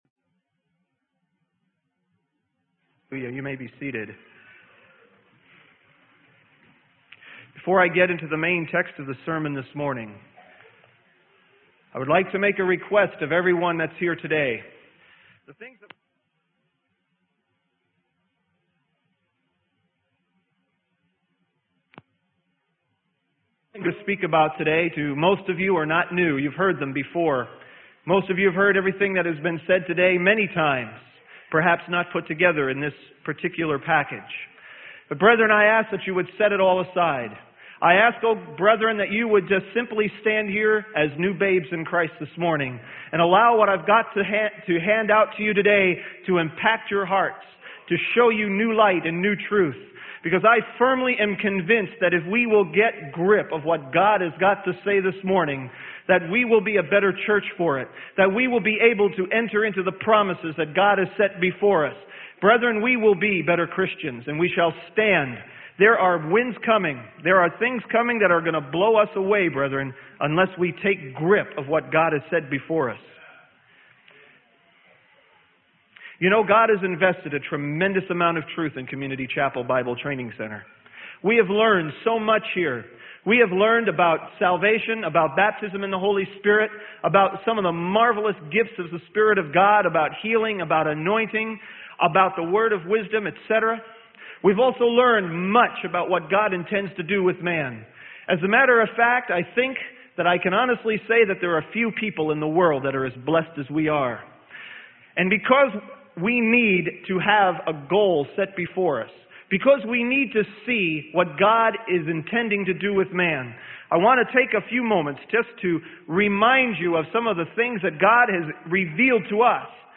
Sermon: Therefore Let Us Walk Eagerly Toward Calvary - Freely Given Online Library